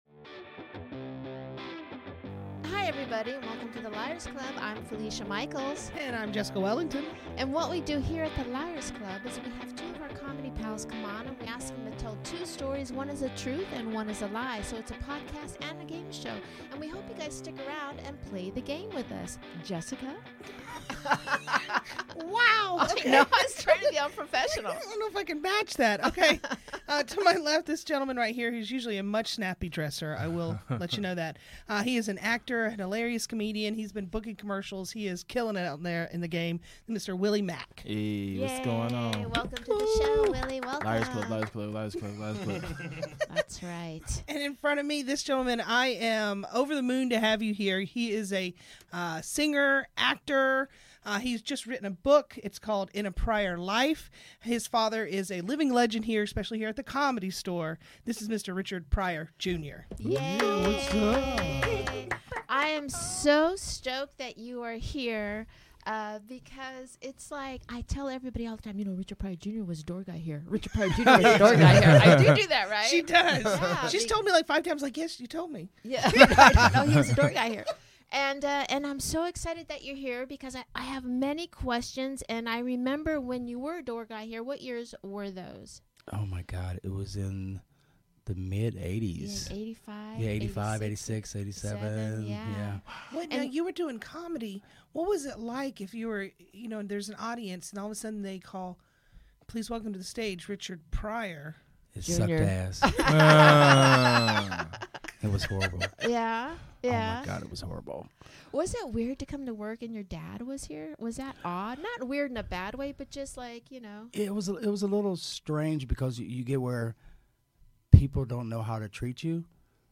Blazing Trees and the Choir Girl! Comedians